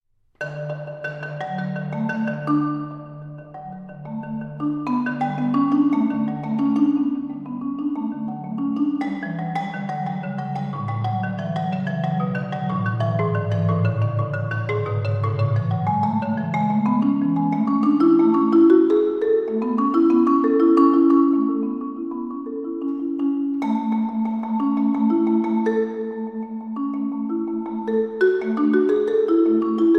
Marimba and Vibraphone